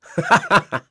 Crow-Vox-Laugh.wav